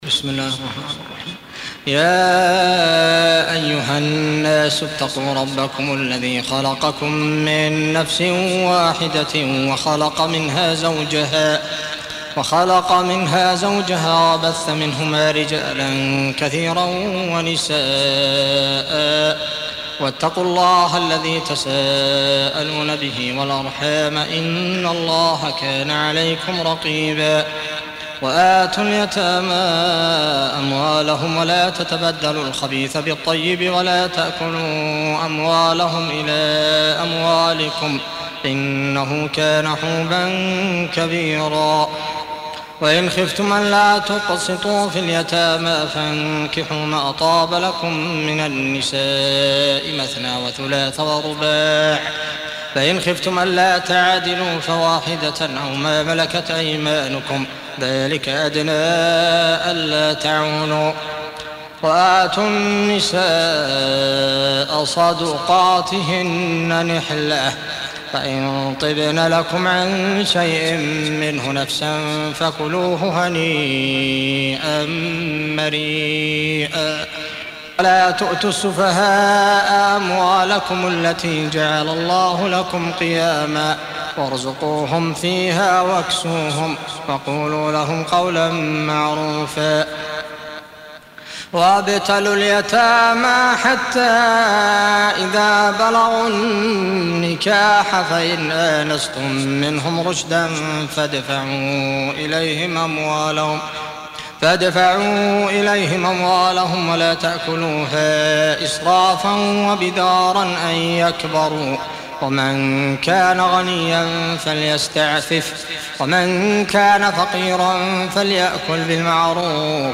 4. Surah An-Nis�' سورة النساء Audio Quran Tarteel Recitation
Surah Repeating تكرار السورة Download Surah حمّل السورة Reciting Murattalah Audio for 4.